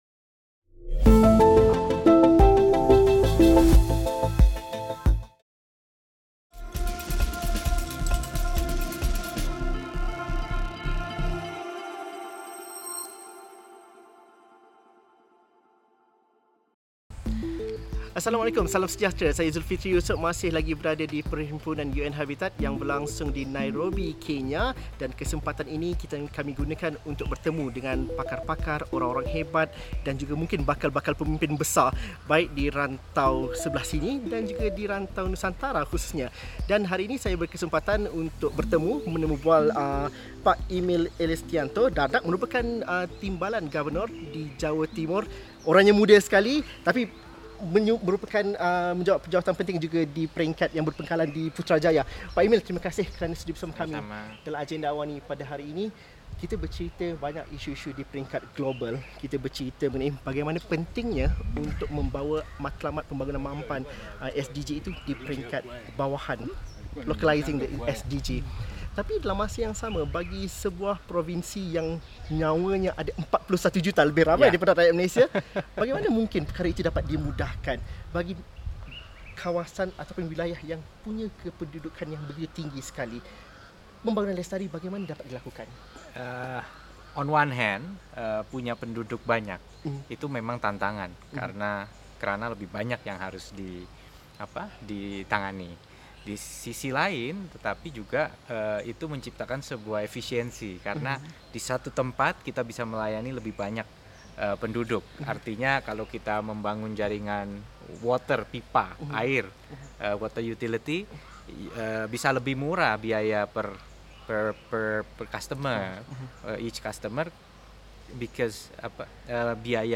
Ikuti temu bual khas bersama Wakil Gubernur Jawa Timur, Emil Elestianto Dardak mengenai apa tindakan dan amalan pembangunan mampan di Indonesia dan bagaimana perlunya ada perkongsian kepakaran bersama dalam membangunkan perbandaran lestari.